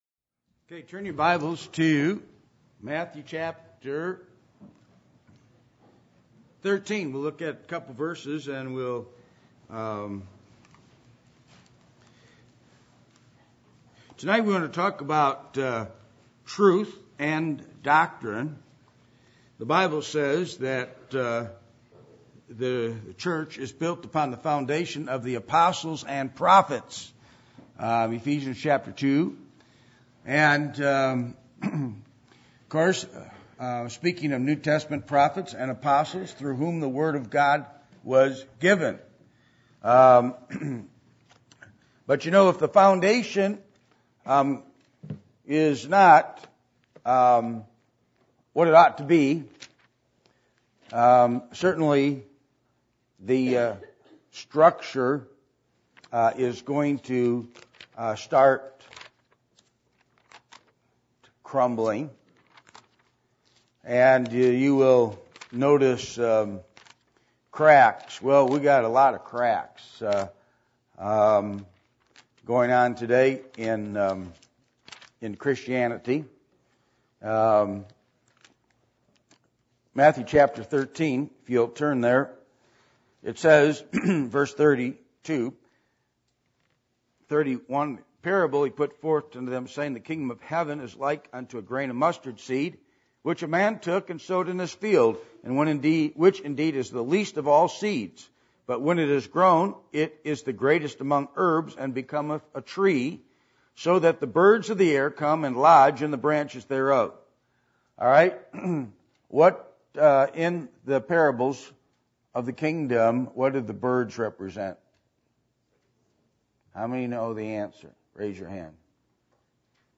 Hosea 4:6 Service Type: Midweek Meeting %todo_render% « Enduring Forever How To Interpret The Bible